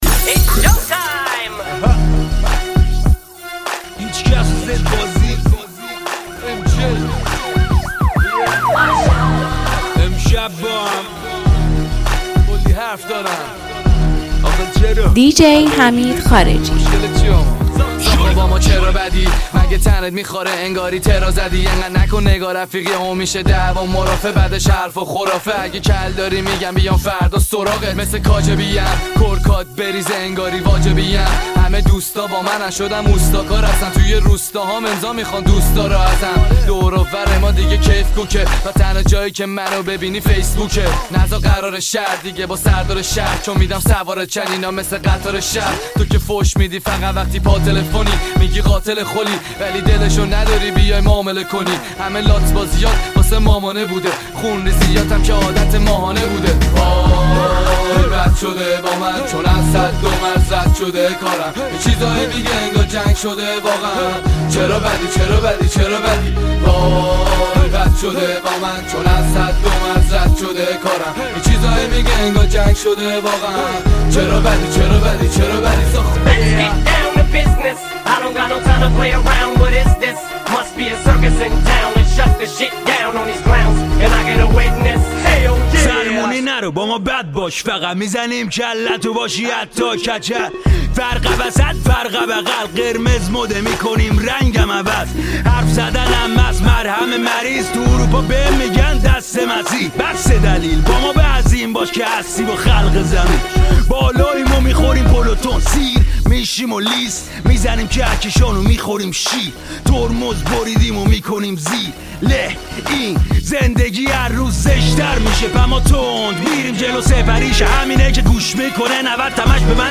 ژانر: رپ
توضیحات: گلچین بهترین ریمیکس های رپ فارسی